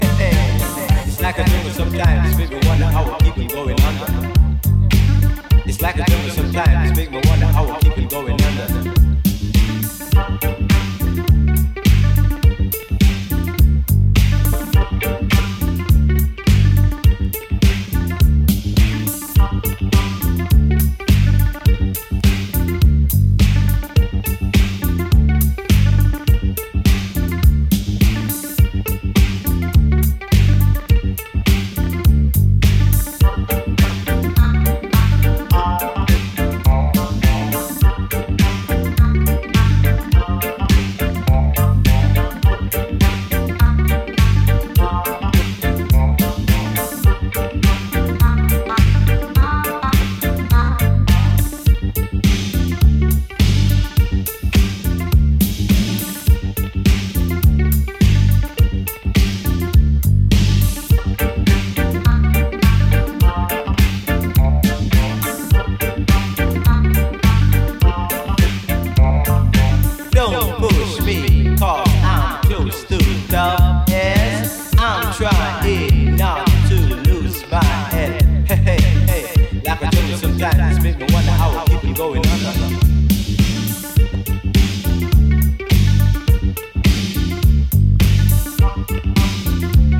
ジャンル(スタイル) NU DISCO / RE-EDIT